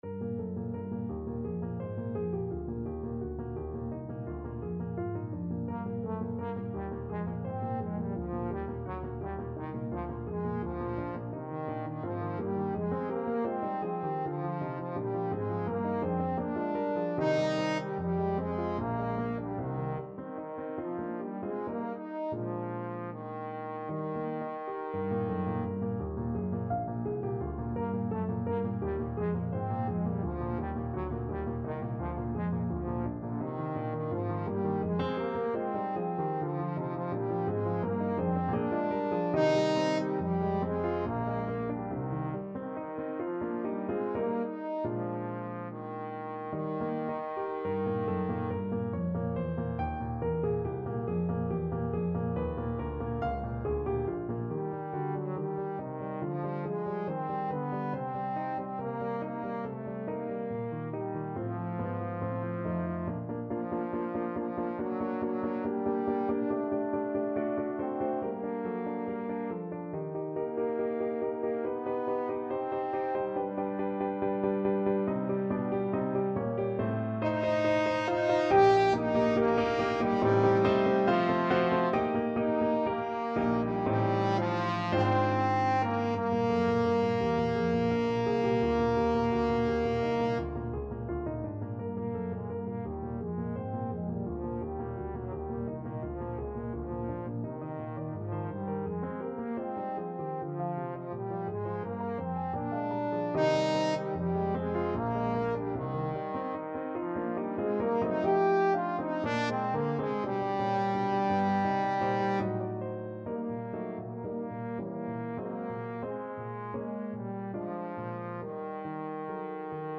Trombone version
4/4 (View more 4/4 Music)
Allegretto = 85 Allegretto
Classical (View more Classical Trombone Music)